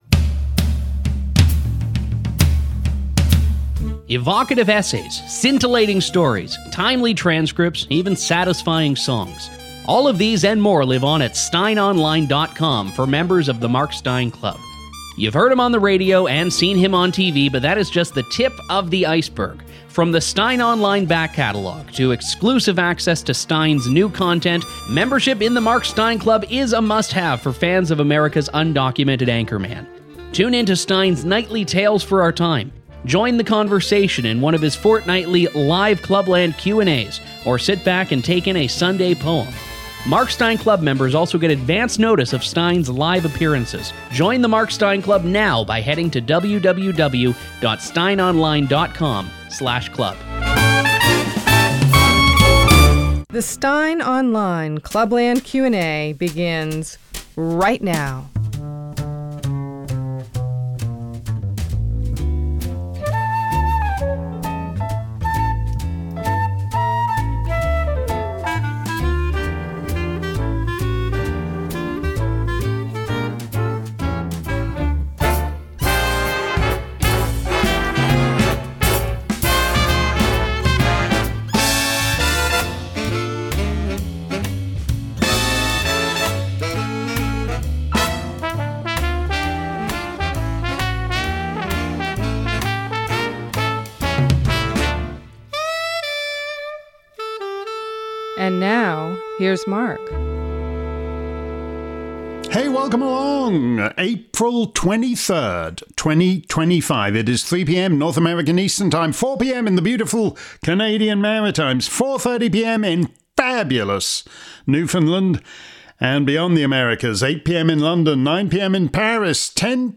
If you missed today's edition of Steyn's Clubland Q&A live around the planet, here's the action replay. This week's show covered a range of topics from Klaus Schwab's unhappy ending to the Canadian election via Chief Justice Roberts' Easter surprise.